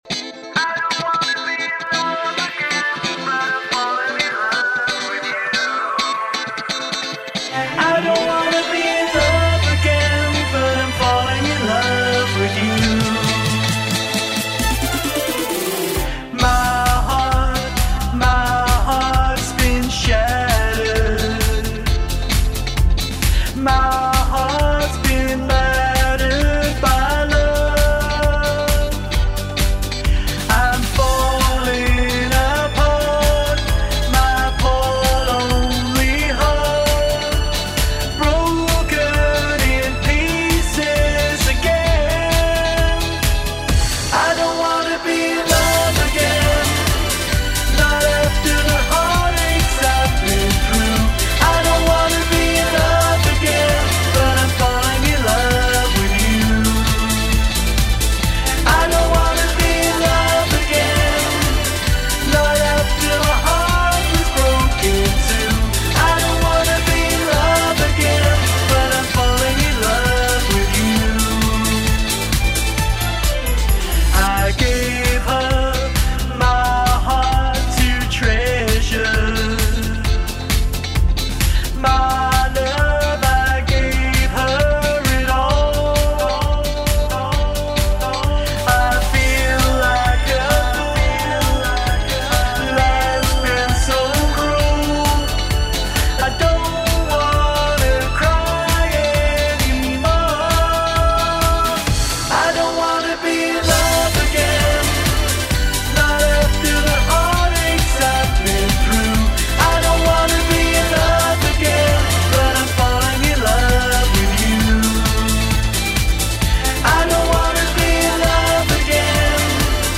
UK pop artist